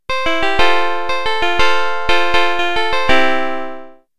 I have a simple virtual analog synthesizer going that can be used to synthesize piano-like sounds.
Sample 1 (C major)
sample-cmajor1.mp3